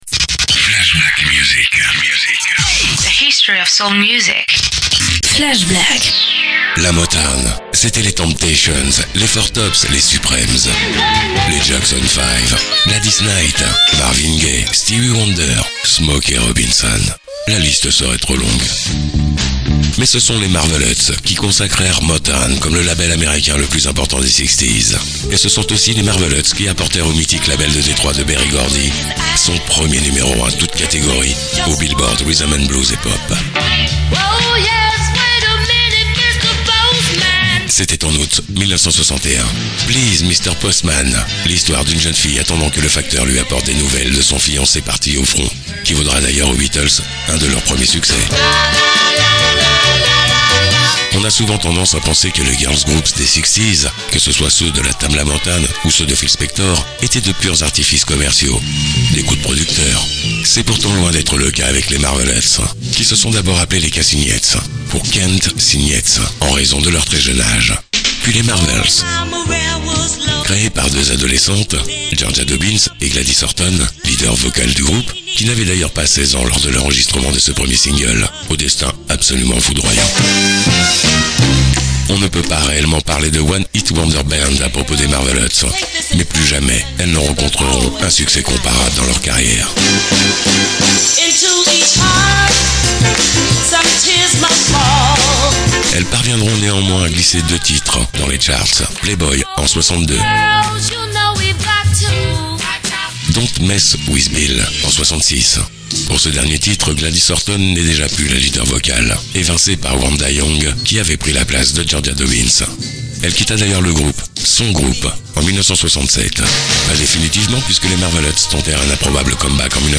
Radio extrait
extrait-radio.mp3